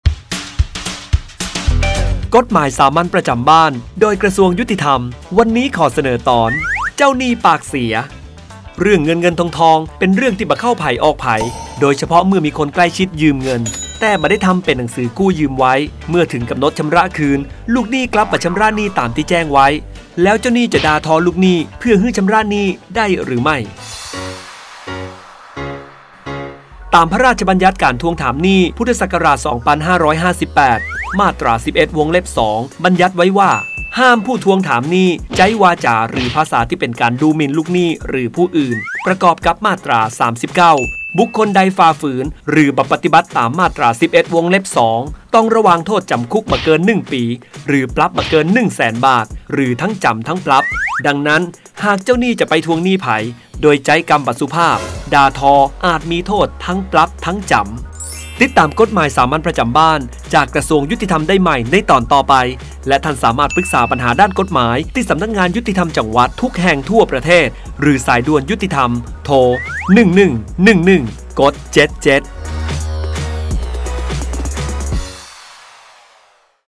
กฎหมายสามัญประจำบ้าน ฉบับภาษาท้องถิ่น ภาคเหนือ ตอนเจ้าหนี้ปากเสีย
ลักษณะของสื่อ :   คลิปเสียง, บรรยาย